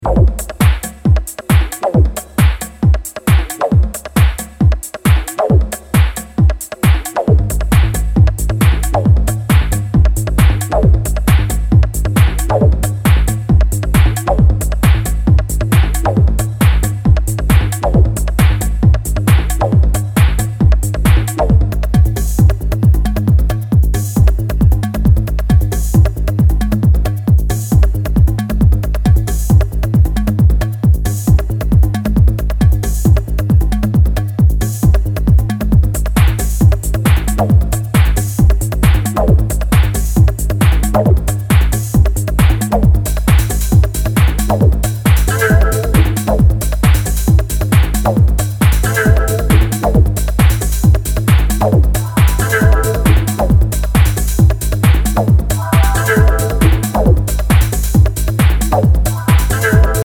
ヴォコーダー/コードを揺らめかせ淡々と推進力抜群のテック・ハウス